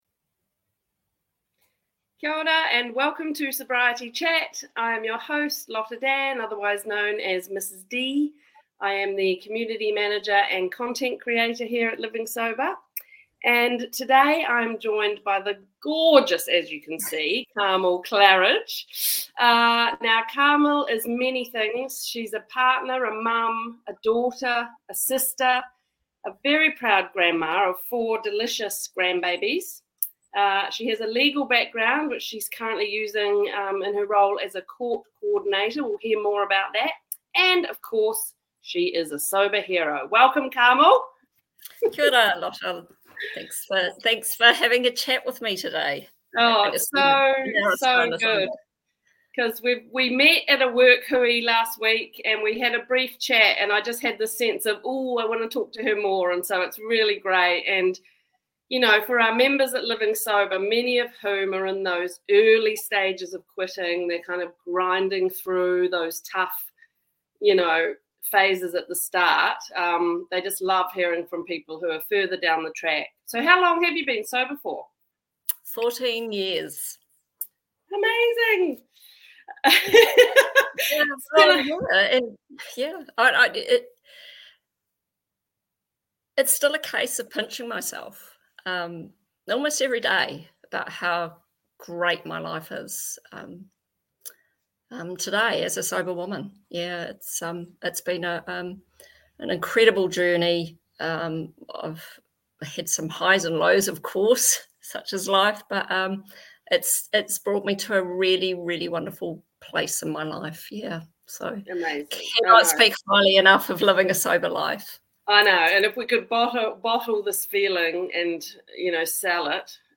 May 27th, 2024 Interviews 1 comment